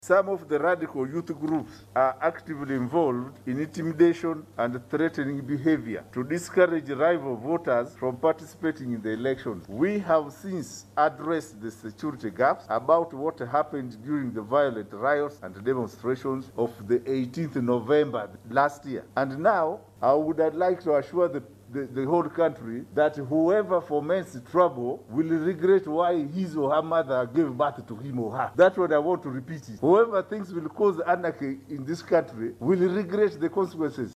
AUDIO! Buli agenda okwekalakaasa agenda kwejjusa lwaki yazaalibwa, IGP Martin Ochola alabudde Bannayuganda mu bukambwe
Ochola abadde omukambwe agumizza eggwanga ku nsonga y’ebyokwerinda era abakozi b’effujjo, tewali kubattira ku liiso.